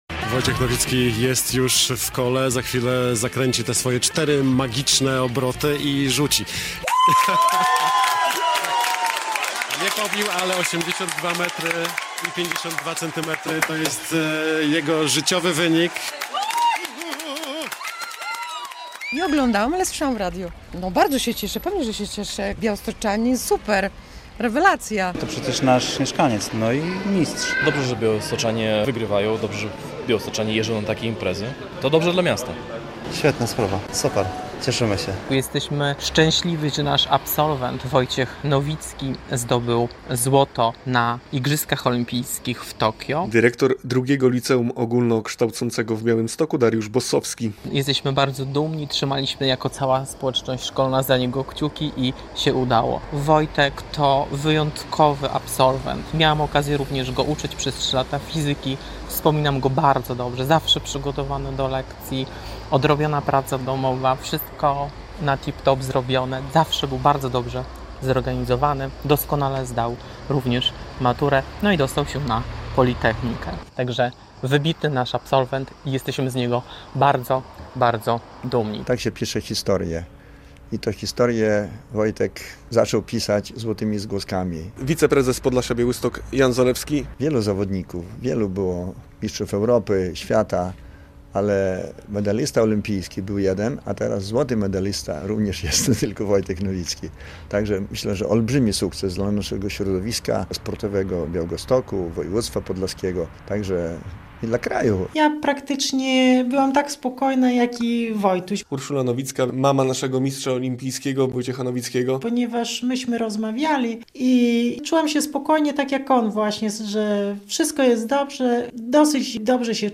Wojciech Nowicki mistrzem olmpijskim - relacja